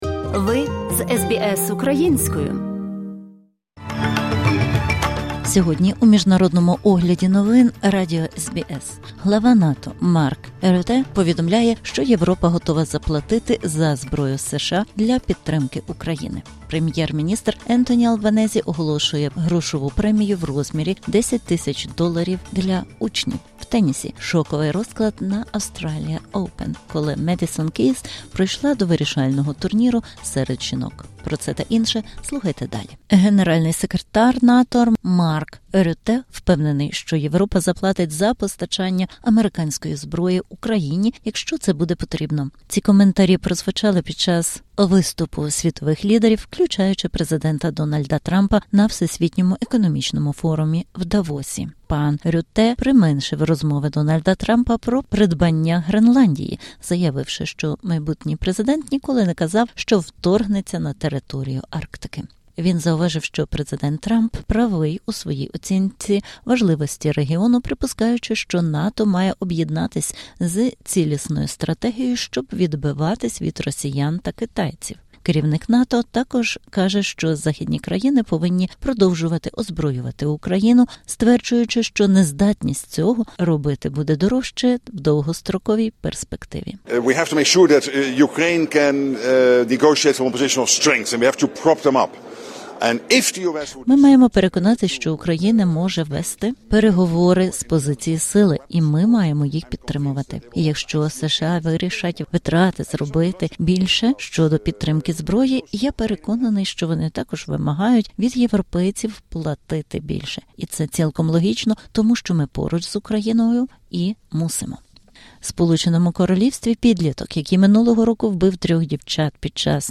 Огляд SBS новин українською мовою.